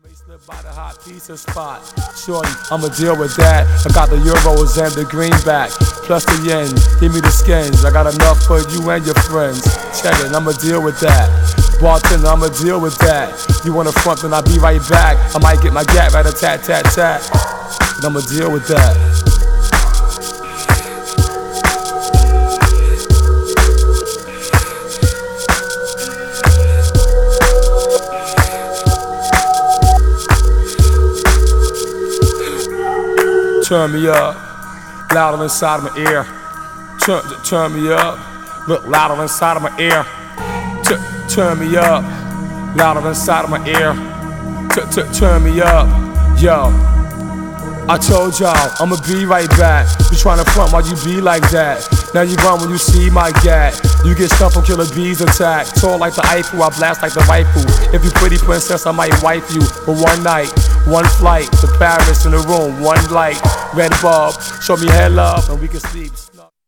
Styl: Hip Hop, House, Breaks/Breakbeat